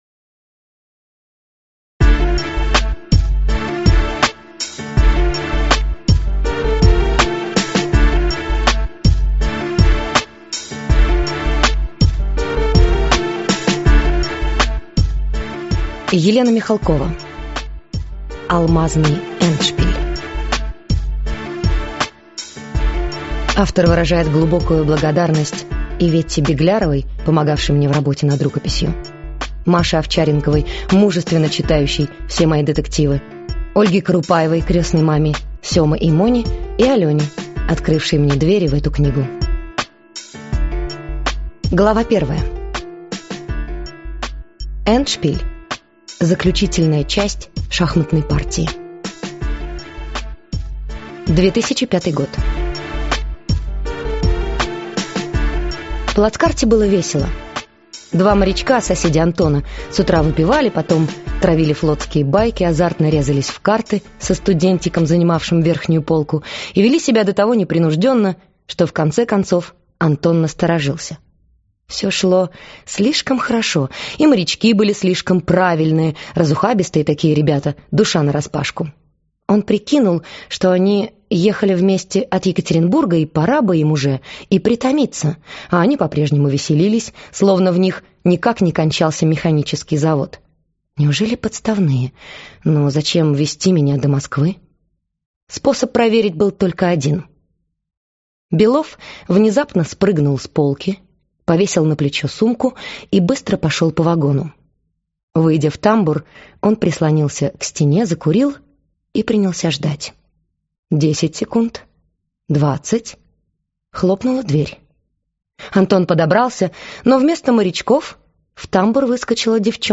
Аудиокнига Алмазный эндшпиль - купить, скачать и слушать онлайн | КнигоПоиск